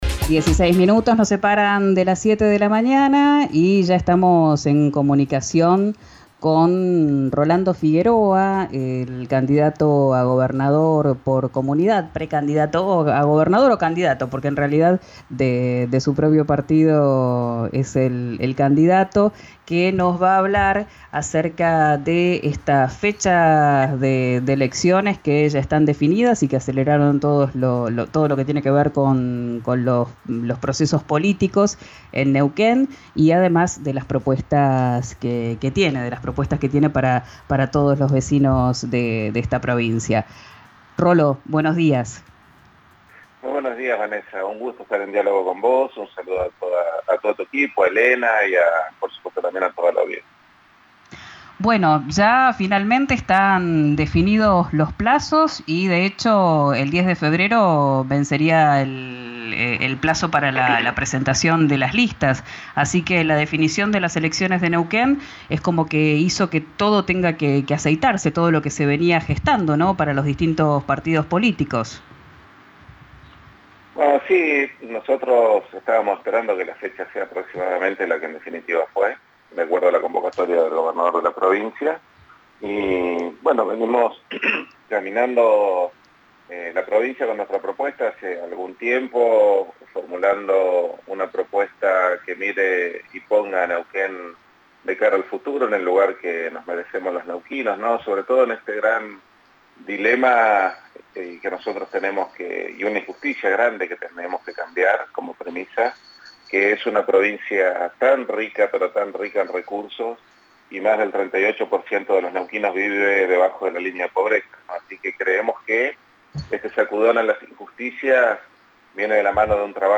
El candidato de 'Comunidad' contó en 'Quién dijo verano', por RÍO NEGRO RADIO, que prepara nuevas alianzas de cara a los comicios del 16 de abril.